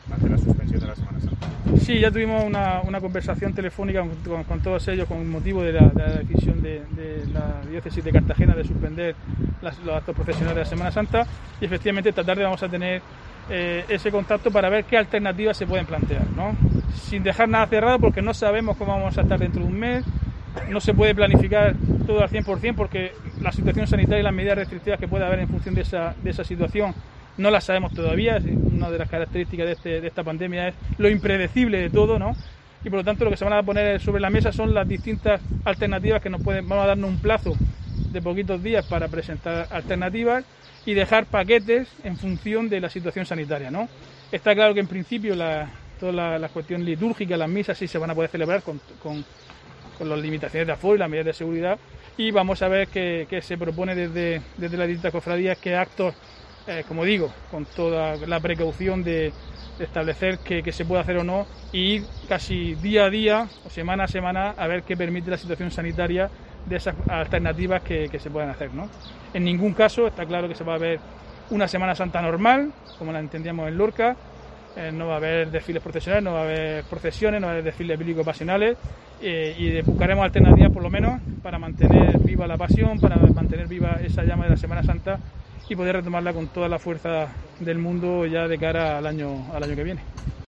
Diego José Mateos, alcalde de Lorca sobre reunión Semana Santa